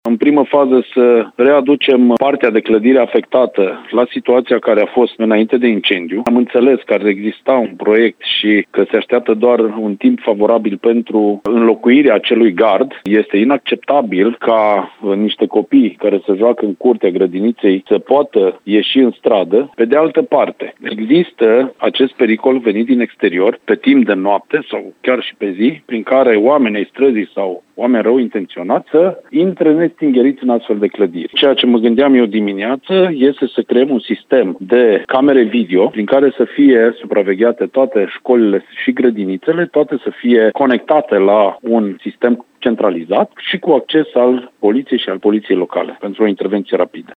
Grădinița nu are gard, oricine poate intra acolo, explică viceprimarul Cosmin Tabără, care nu exclude ca cei care au dat foc acoperișului să fi fost oamenii străzii.